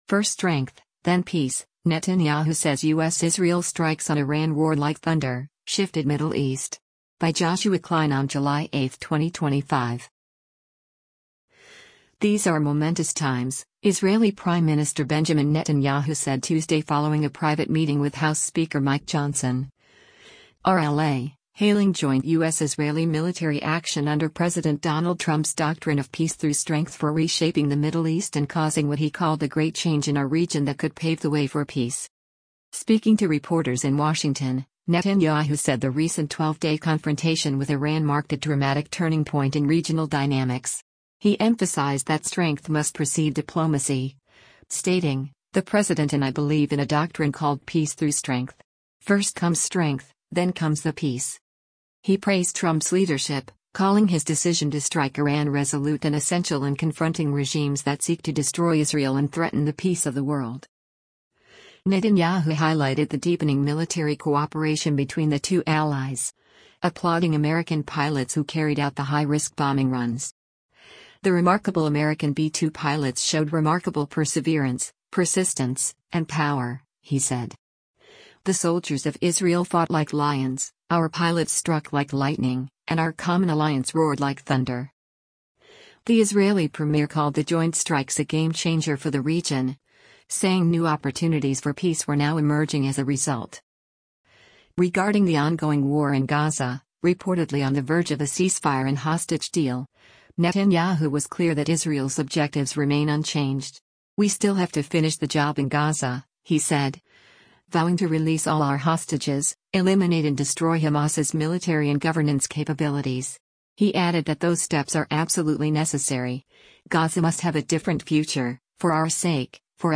Benjamin Netanyahu, Israel's prime minister, speaks to members of the media following a me
Speaking to reporters in Washington, Netanyahu said the recent 12-day confrontation with Iran marked a dramatic turning point in regional dynamics.